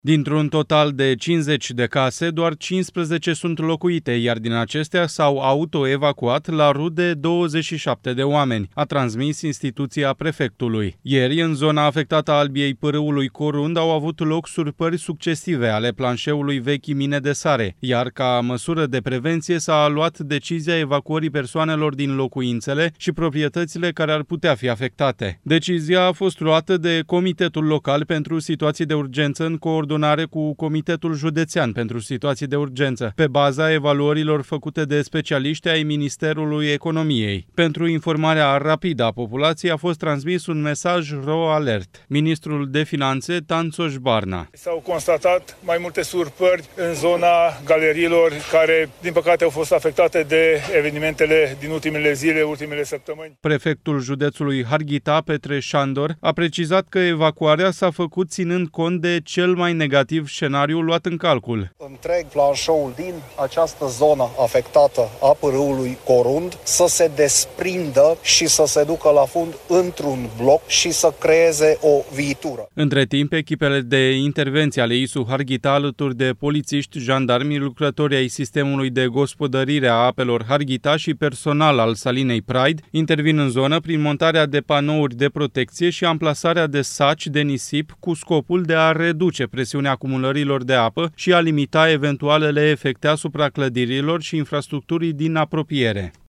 “S-au constatat mai multe surpări în zona galeriilor care, din păcate, au fost afectate de evenimentele din ultimele zile, ultimele săptămâni.” a declarat, la fața locului, vicepremierul și ministru al Finanțelor, Tanczos Barna.
Prefectul judeţului Harghita, Petreș Șandor, a precizat că evacuarea s-a făcut ţinând cont de cel mai negativ scenariu luat în calcul: